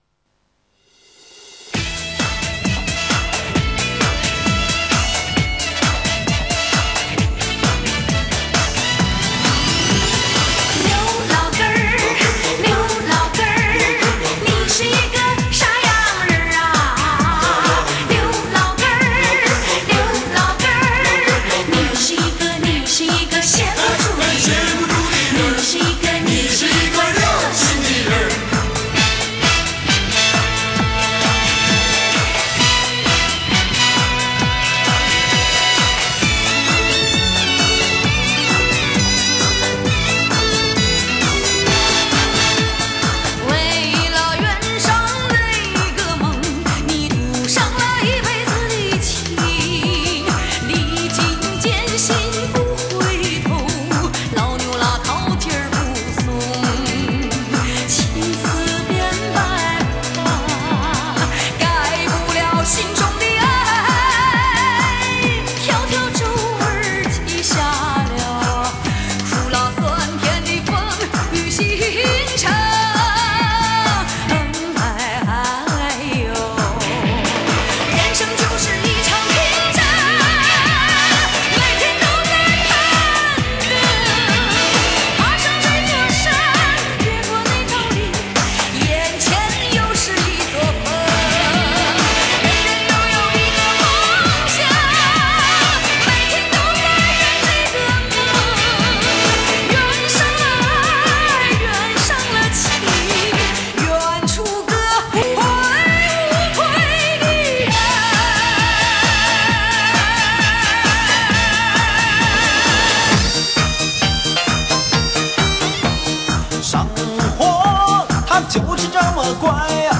（平 四）